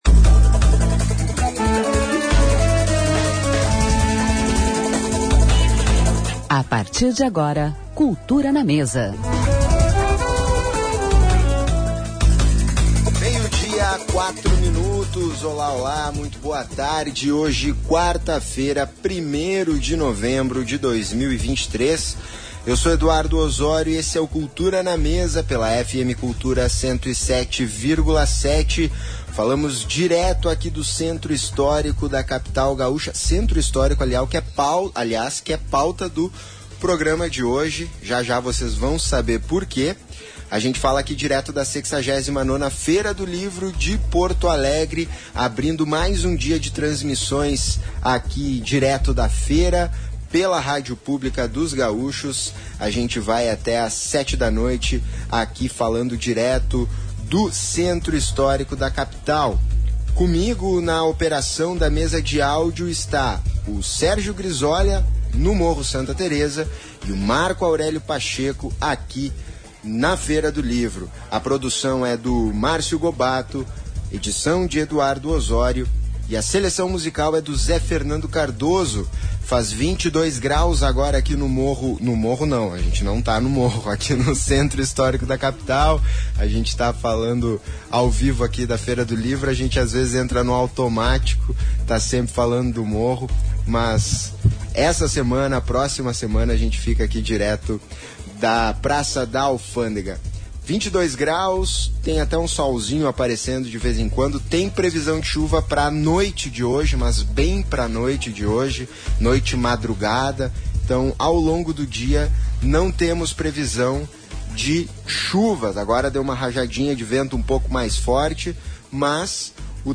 Cultura na Mesa direto da 69ª Feira do Livro de Porto Alegre.